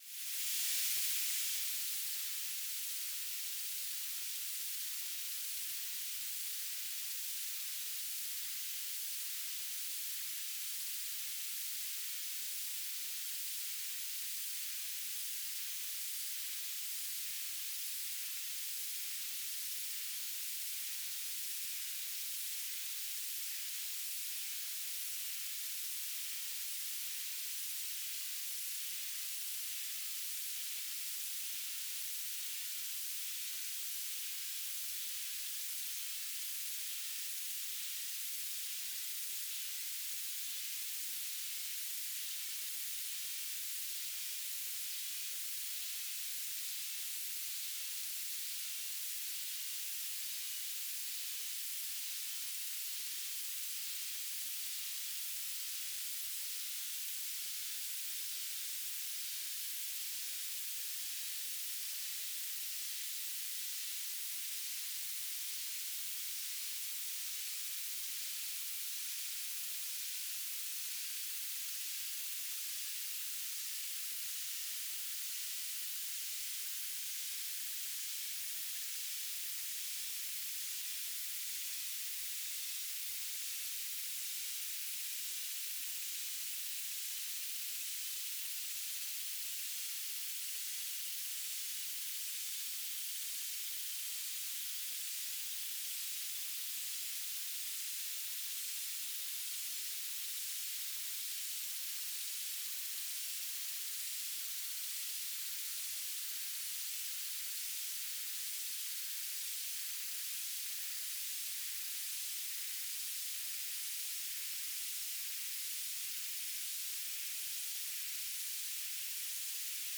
"transmitter_mode": "BPSK",